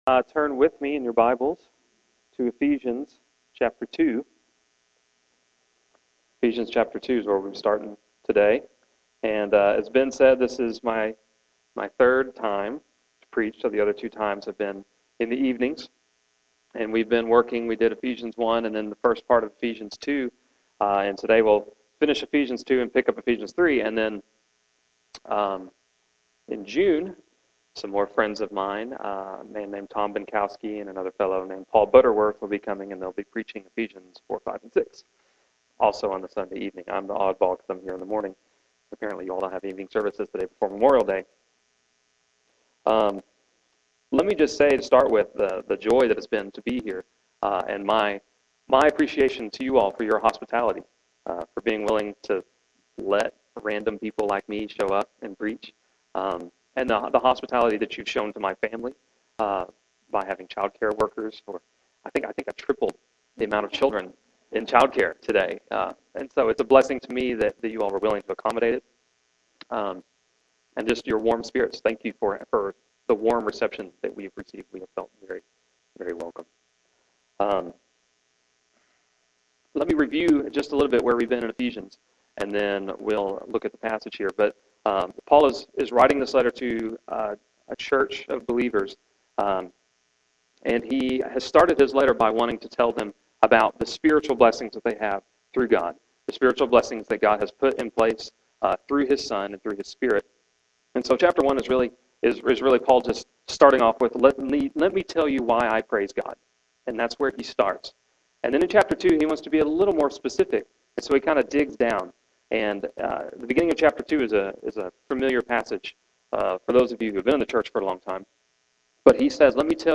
May 30, 2010 AM Worship | Vine Street Baptist Church
This did not dampen the mood of the other members and guests who gathered at Vine Street Baptist Church to worship God.
After a time of welcoming each other with hugs and handshakes, the choir ensemble sang “My Jesus, I Love Thee” and asked the congregation to join on the fourth stanza.